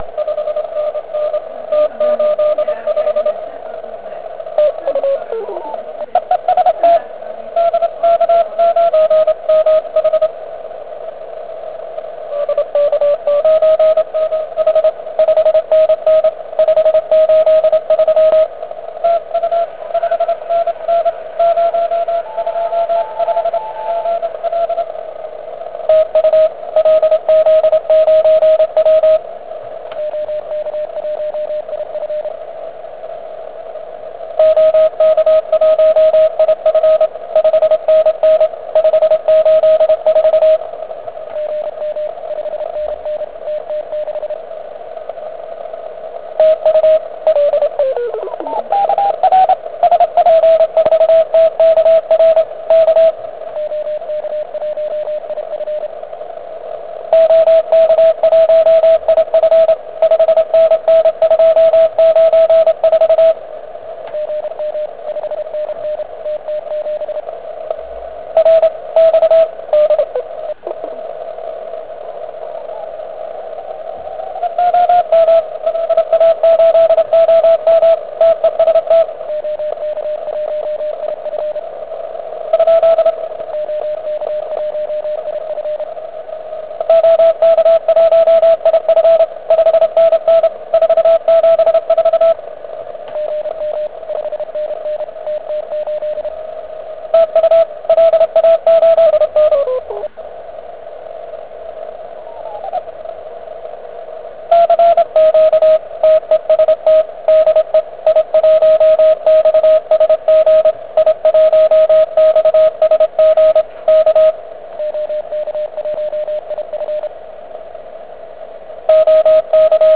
Vyndal jsem ATS-3B a zkusil pro radost v p�smu 40m p�r spojen�.